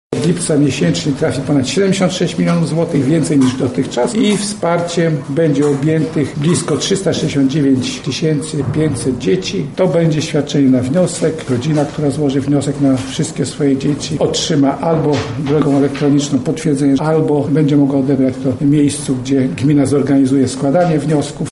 Jak wsparcie programem 500+ będzie wyglądało na Lubelszczyźnie mówi Krzysztof Michałkiewicz, wiceminister Rodziny, Pracy i Polityki Społecznej: